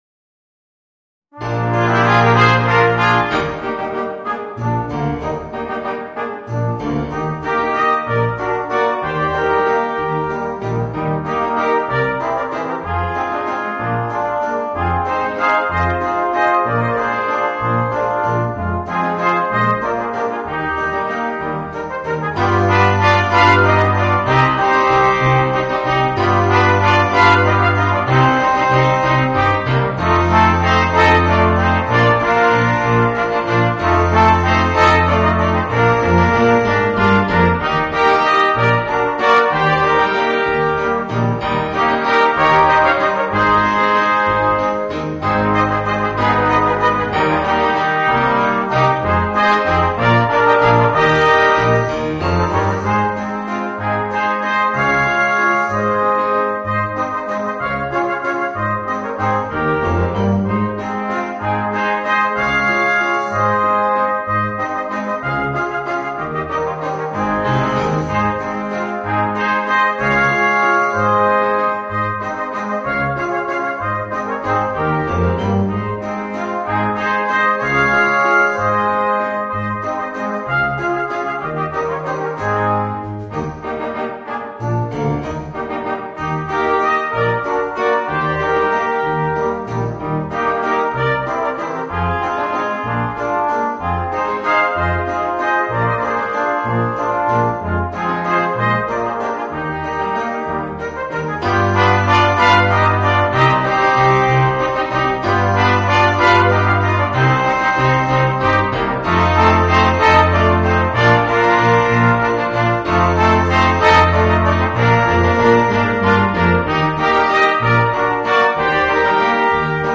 Voicing: Small Ensembles